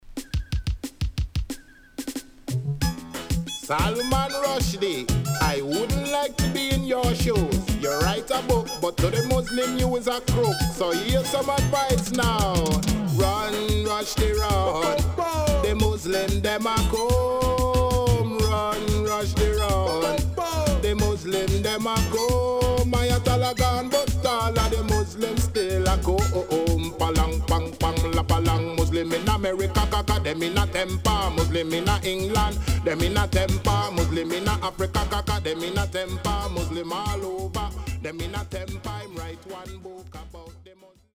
HOME > DISCO45 [DANCEHALL]  >  COMBINATION
SIDE A:少しチリノイズ入りますが良好です。